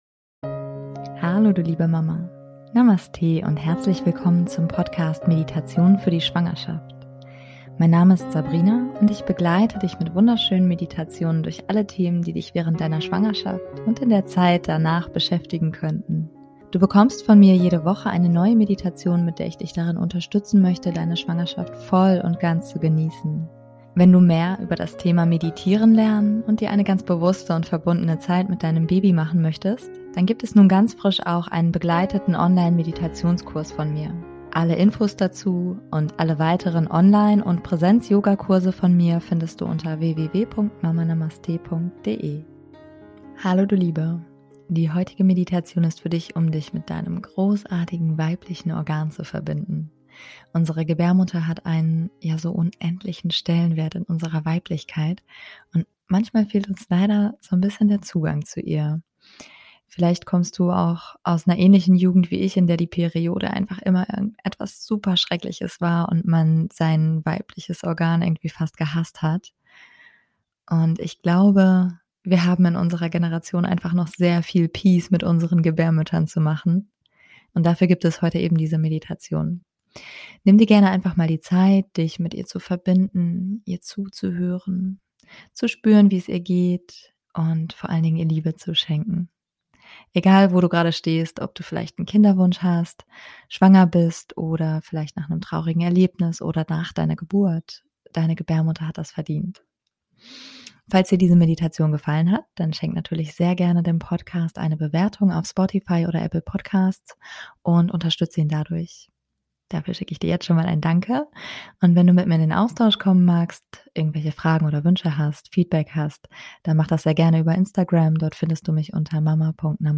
Die heutige Meditation ist für dich, um dich mit deinem großartigen weiblichen Organ zu verbinden.